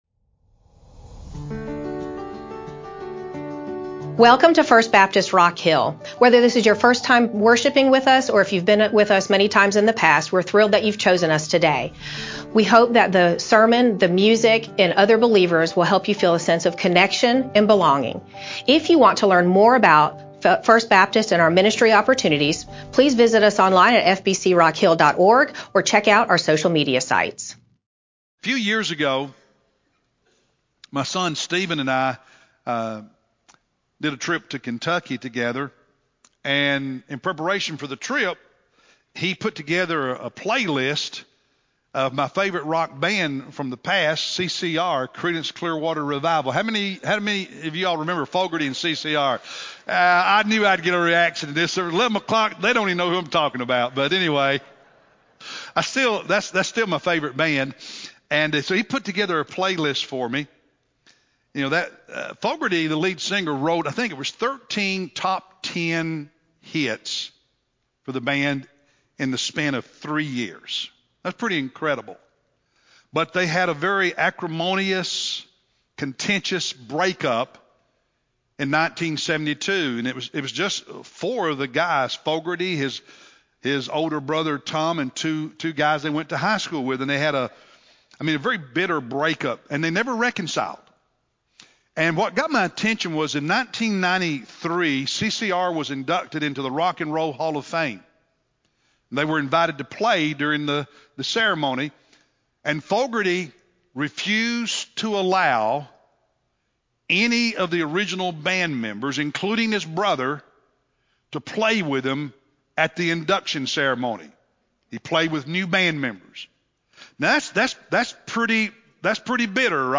April-19-Sermon-CD.mp3